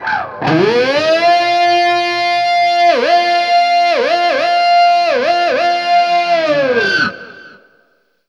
DIVEBOMB13-L.wav